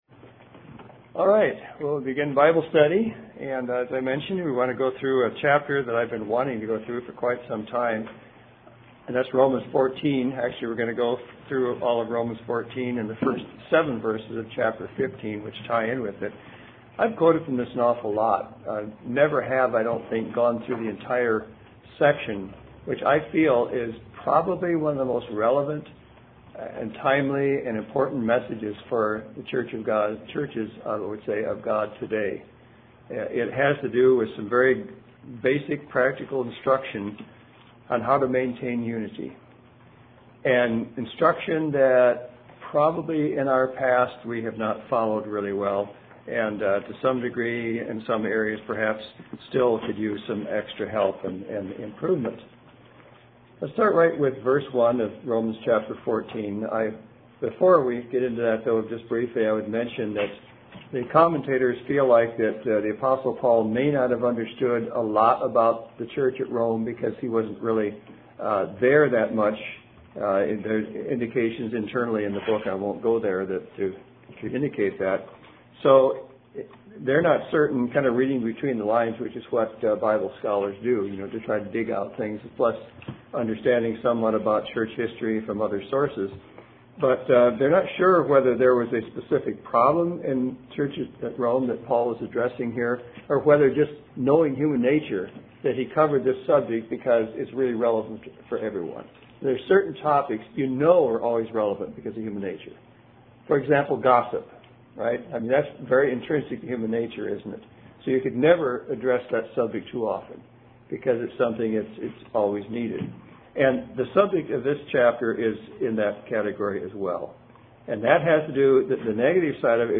Romans 14 contains vital basic instruction on interpersonal relationships within the church and how to maintain unity and promote edification. This Bible study is a verse by verse exposition of this important and timely passage of scripture.